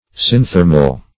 Search Result for " synthermal" : The Collaborative International Dictionary of English v.0.48: Synthermal \Syn*ther"mal\, a. [Pref. syn- + thermal.]